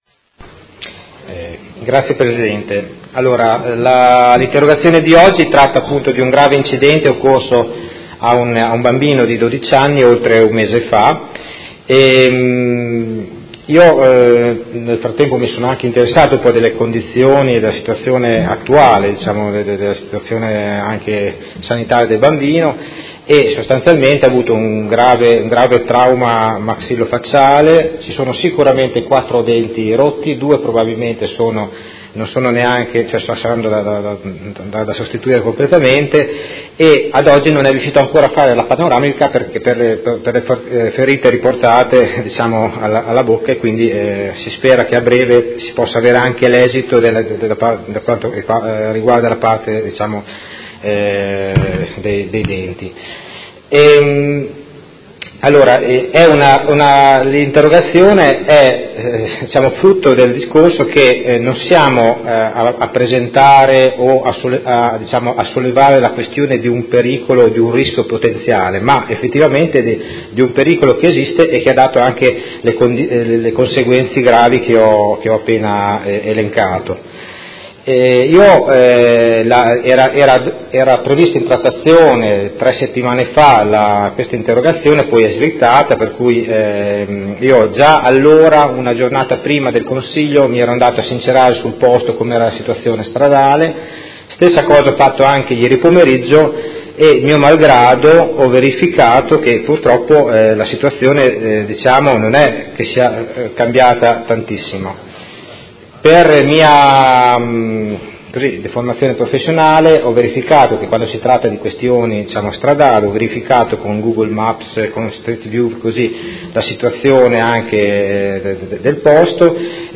Vincenzo Walter Stella — Sito Audio Consiglio Comunale
Seduta del 13/07/2017 Interrogazione del Consigliere Stella (Art.1-MDP) avente per oggetto: Grave incidente occorso a ciclista dodicenne lungo la Strada Albareto